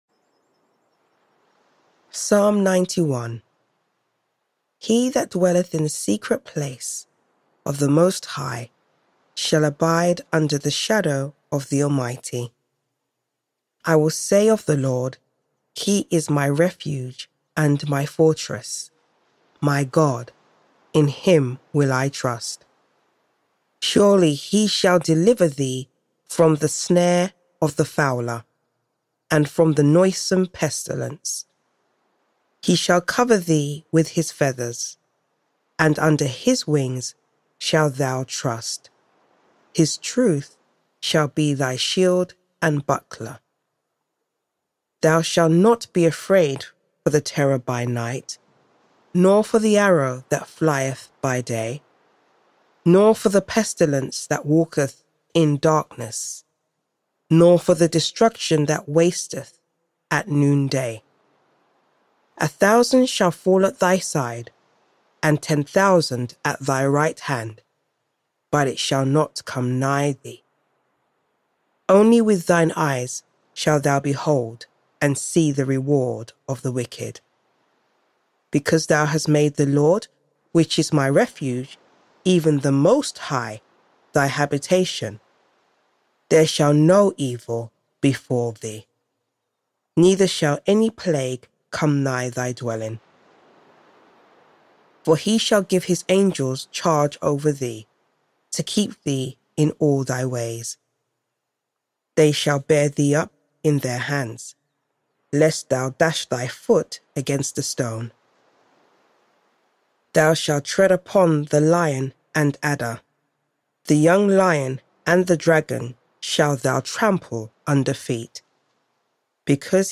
Psalm 91 Scripture Reading